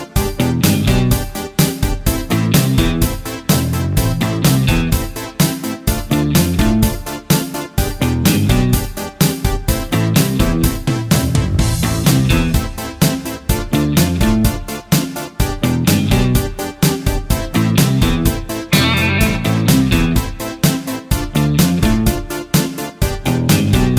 no Backing Vocals Soft Rock 3:45 Buy £1.50